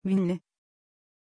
Pronunciation of Winnie
pronunciation-winnie-tr.mp3